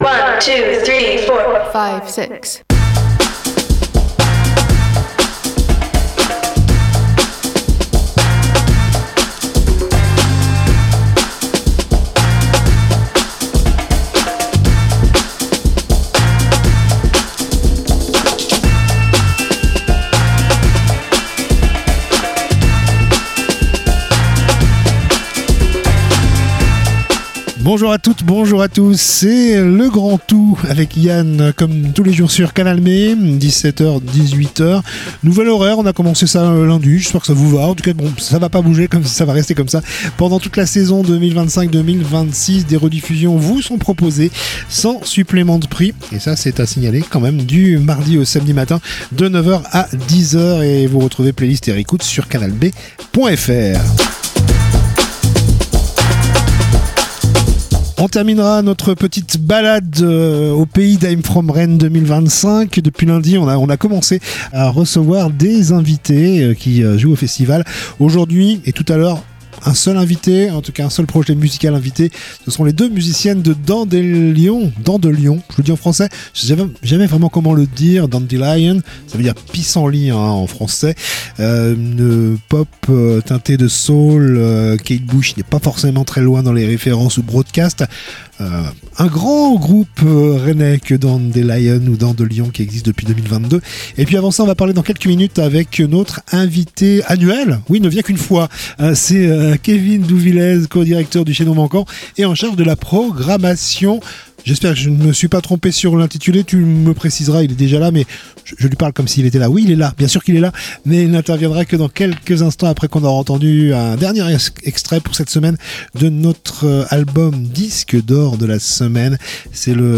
itv